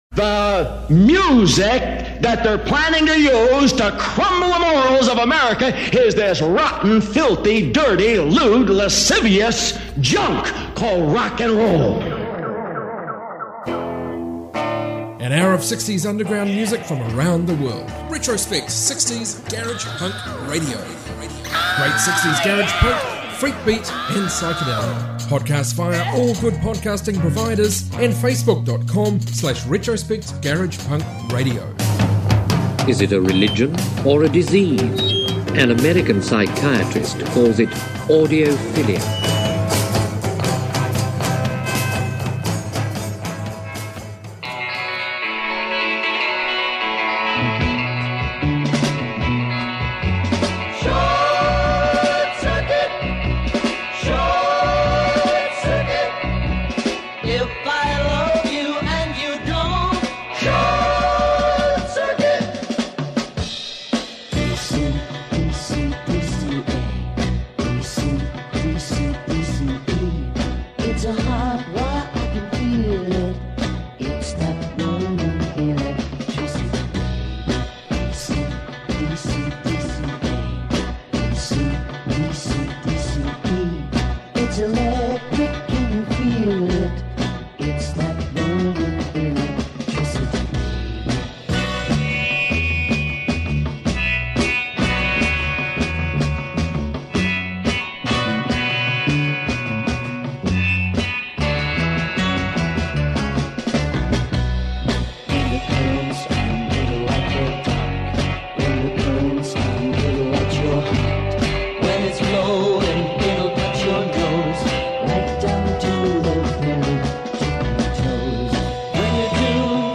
60s garage